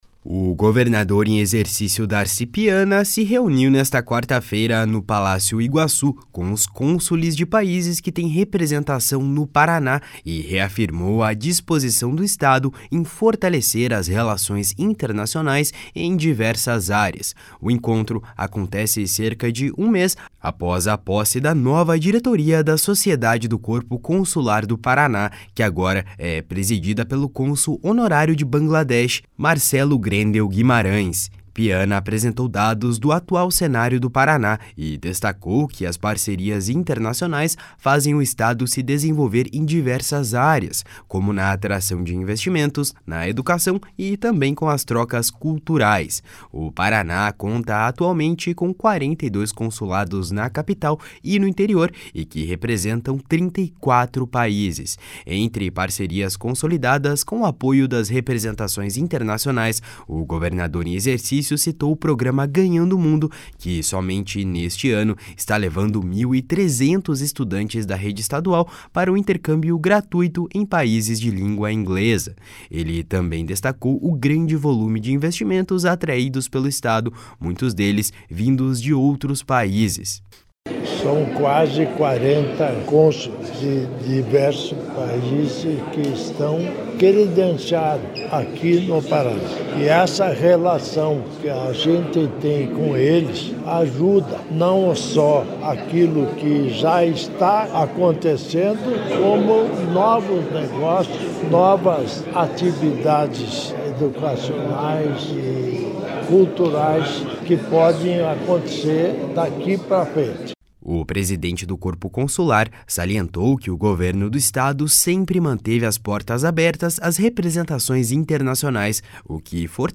// SONORA DARCI PIANA //